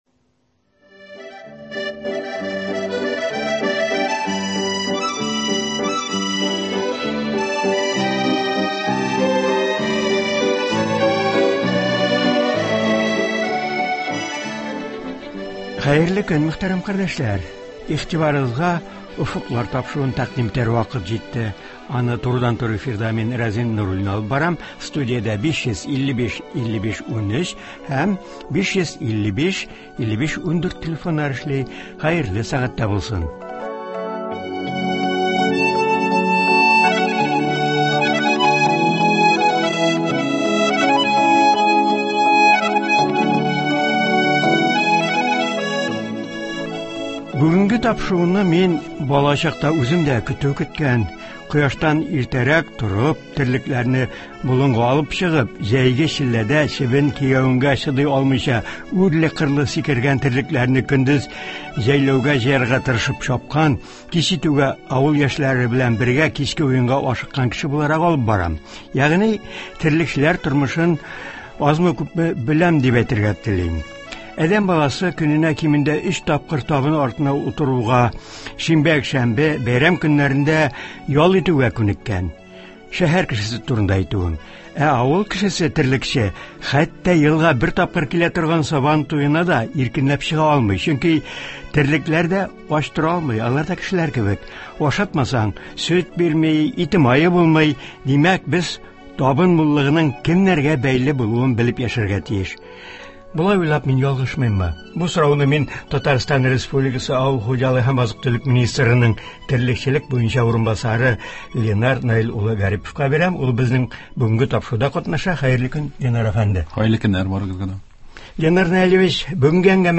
Болар хакында Татарстан республикасы авыл хуҗалыгы һәм азык-төлек министрының терлекчелек буенча урынбасары Ленар Гарипов сөйләячәк һәм тыңлаучылар сорауларына җавап бирәчәк.